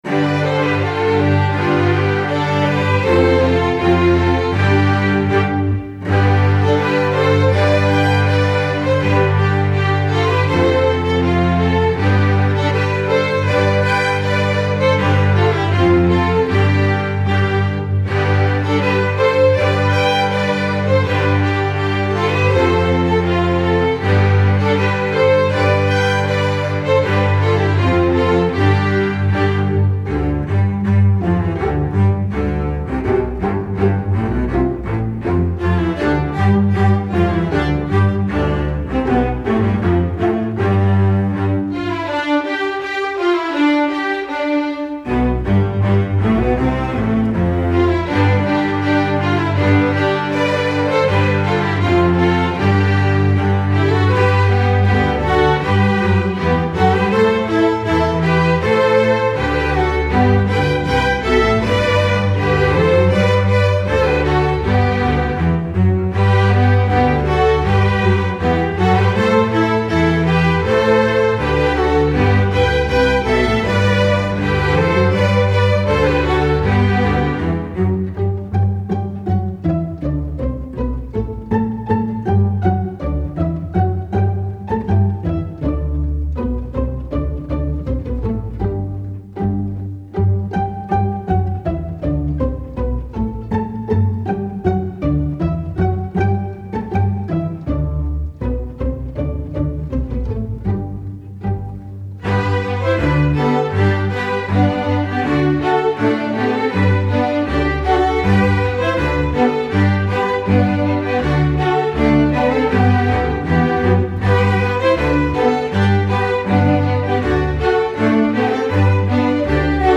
Instrumentation: string orchestra
folk, traditional